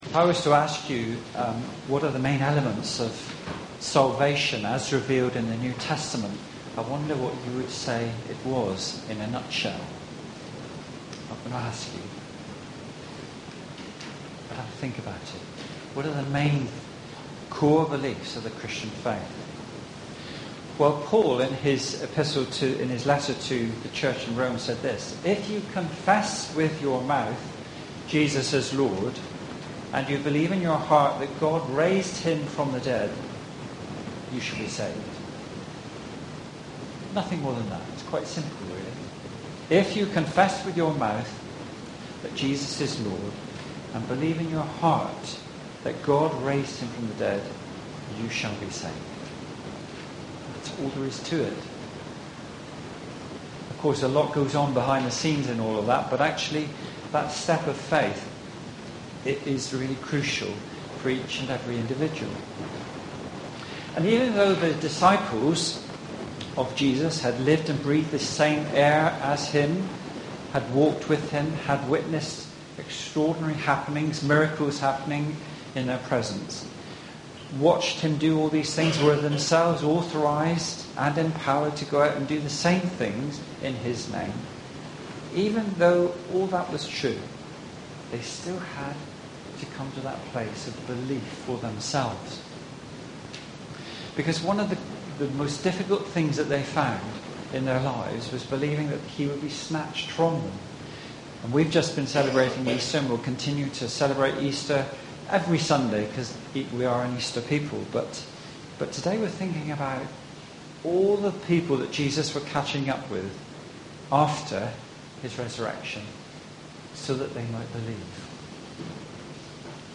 Sermon-8-April-2018.mp3